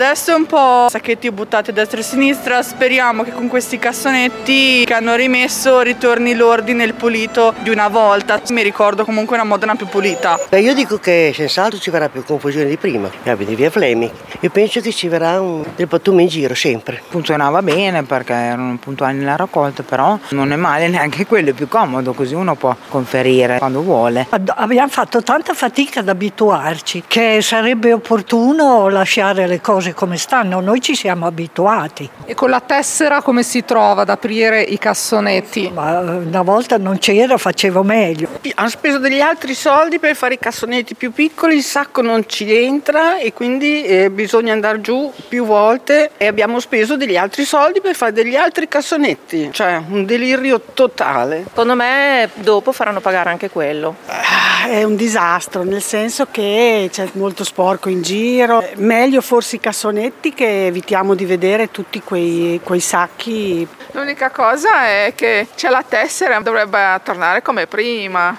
Differenziata, si torna ai cassonetti. Le interviste al Villaggio Giardino
Sentiamo nelle interviste qui sotto come è stata accolta la novità dai residenti
VOX-VILLAGGIO-GIARDINO.mp3